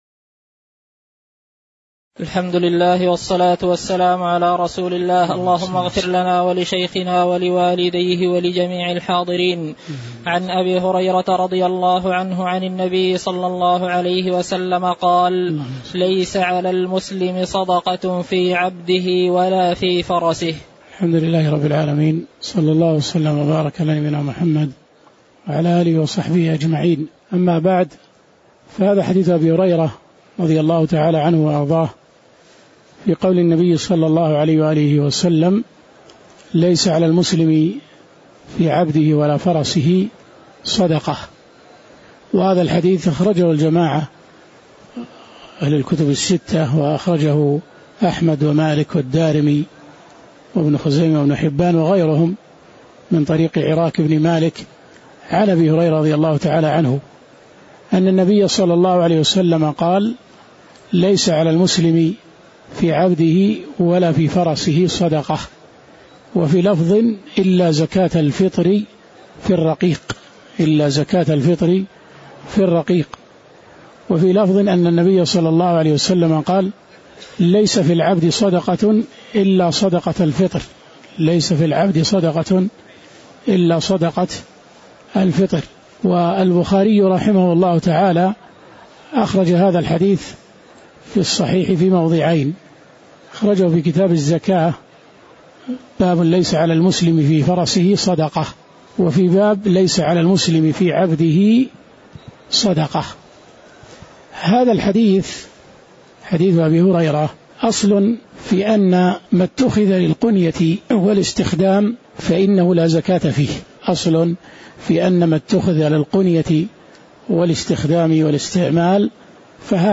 تاريخ النشر ٧ رمضان ١٤٣٩ هـ المكان: المسجد النبوي الشيخ